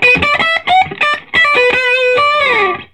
Index of /90_sSampleCDs/USB Soundscan vol.22 - Vintage Blues Guitar [AKAI] 1CD/Partition C/13-SOLO B060